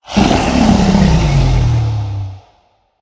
Minecraft Version Minecraft Version snapshot Latest Release | Latest Snapshot snapshot / assets / minecraft / sounds / mob / enderdragon / growl1.ogg Compare With Compare With Latest Release | Latest Snapshot
growl1.ogg